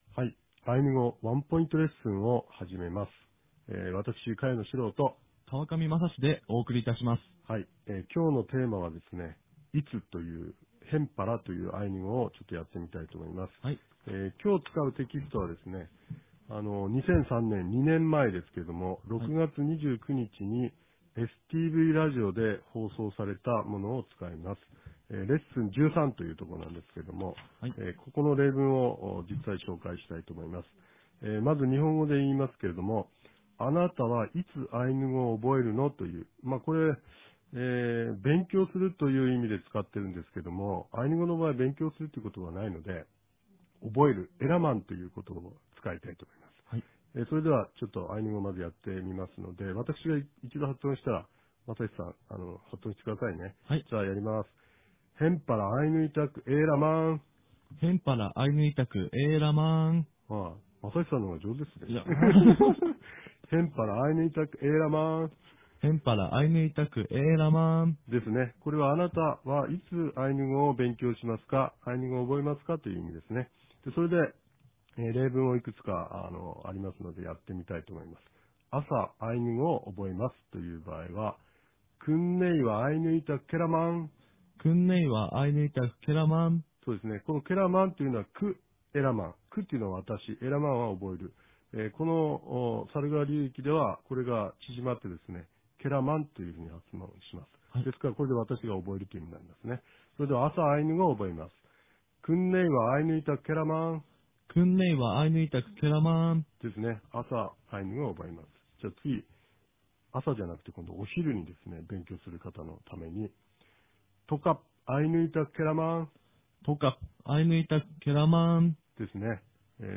■アイヌ語ワンポイントレッスン（１４）